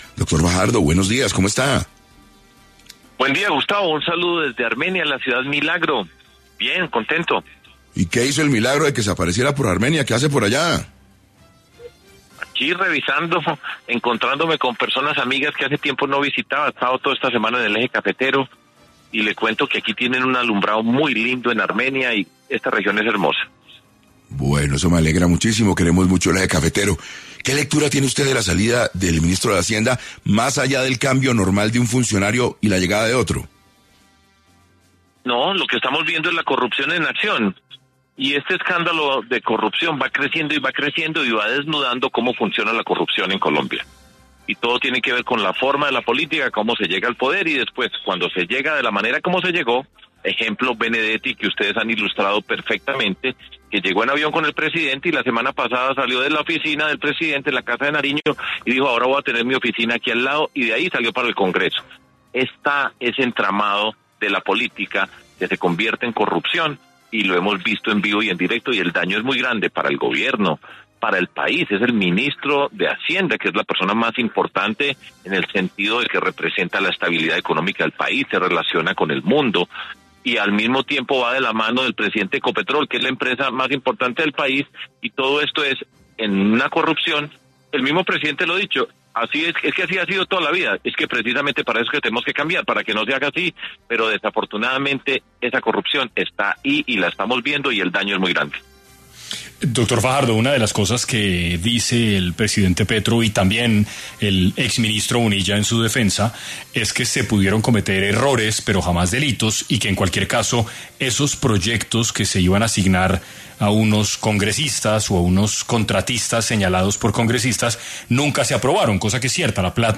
En 6AM de Caracol Radio estuvo Sergio Fajardo, excandidato presidencia, quien hablo sobre cuál será el futuro del gobierno Petro tras la salida de Ricardo Bonilla y su investigación por presunta corrupción en la UNGRD.